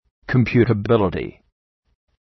Προφορά
{kəm,pju:tə’bılətı}